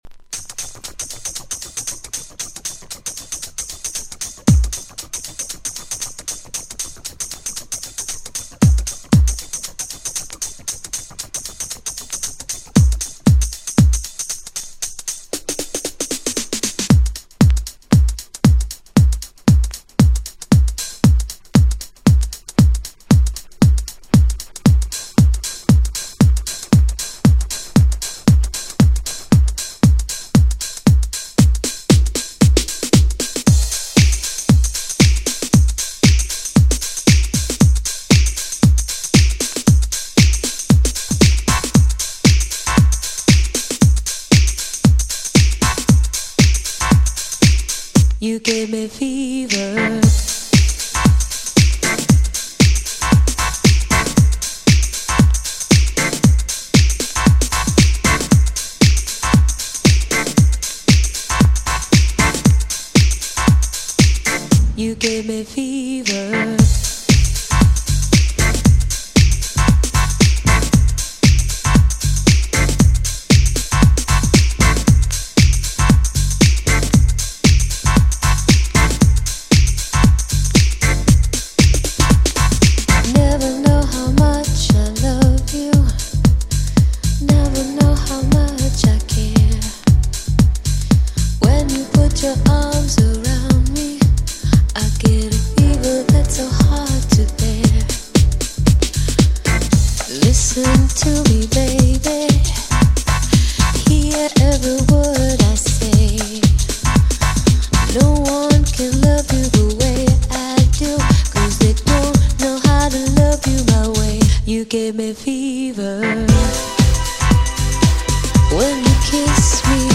GENRE House
BPM 116〜120BPM
じわじわ盛り上がる # アンビエント # クロスオーバー # ダーク # 妖艶